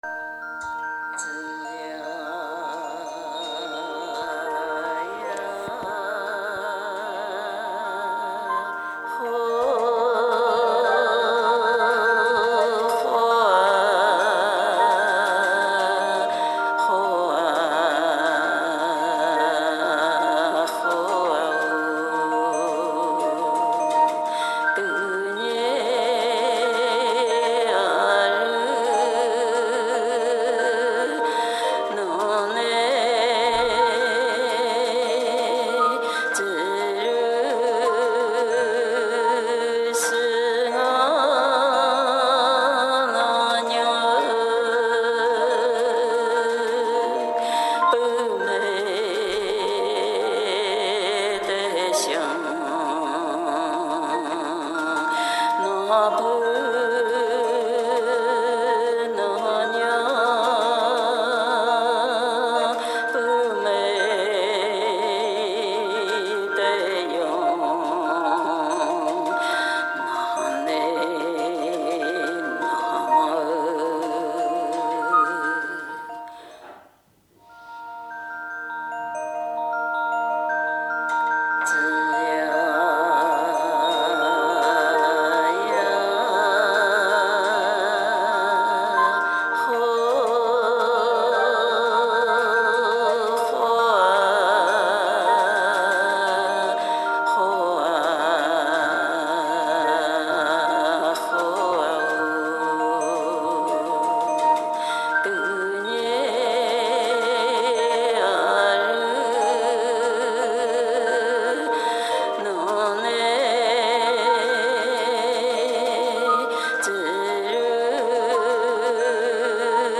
女声古歌腔.mp3